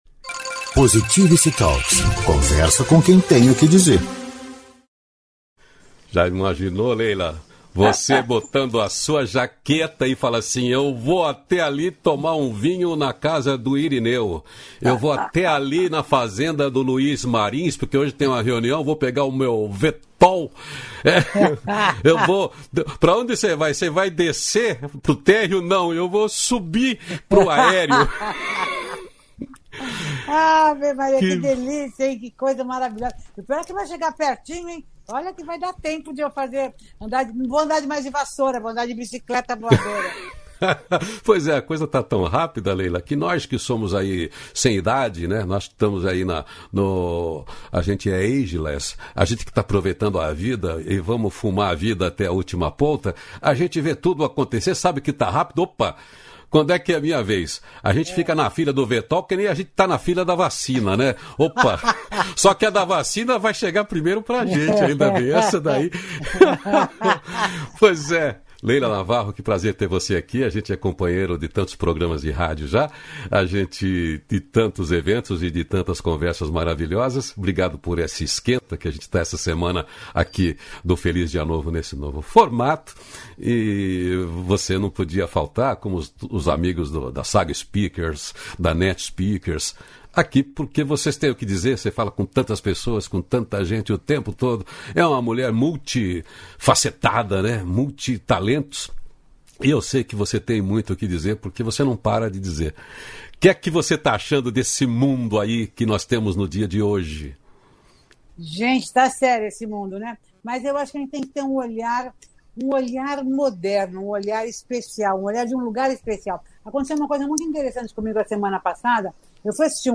235-feliz-dia-novo-entrevista.mp3